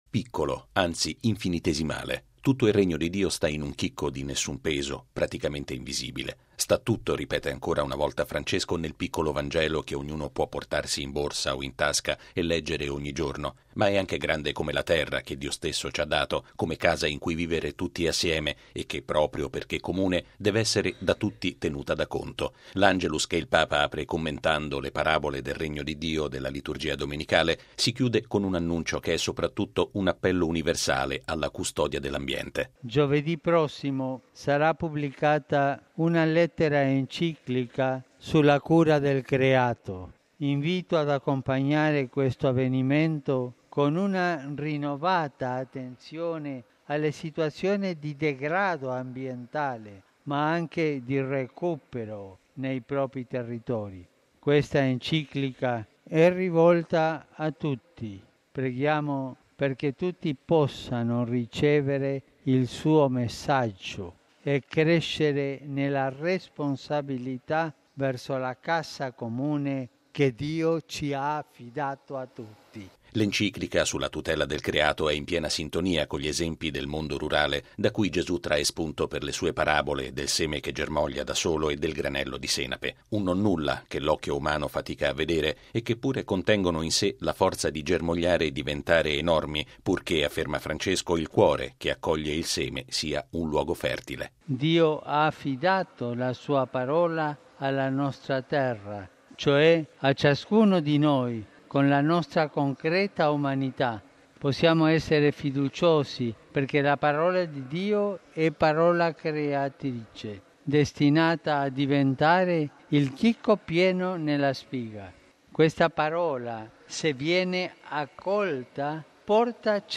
È l’auspicio che Papa Francesco ha espresso al termine dell’Angelus in Piazza San Pietro, dopo aver ricordato che giovedì prossimo verrà presentata la sua Enciclica “sulla cura del creato”.